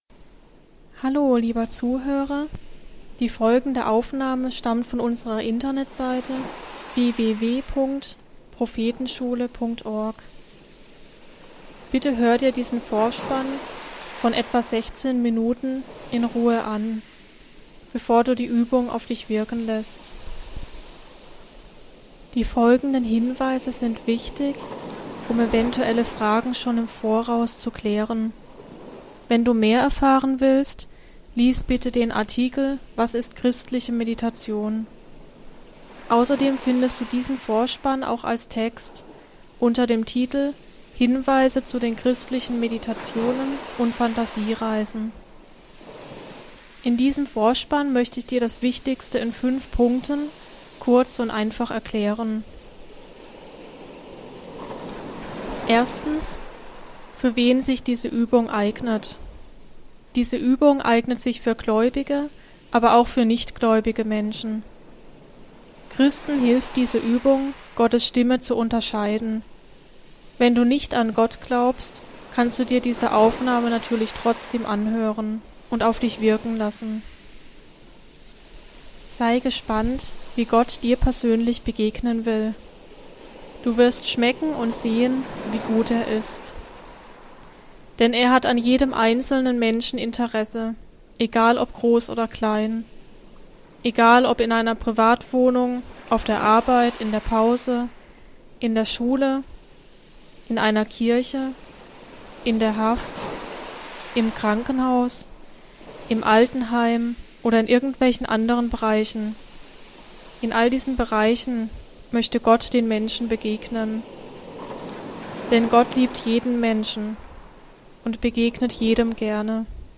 der-schc3b6nste-rosengarten-der-welt-phantasiereise.mp3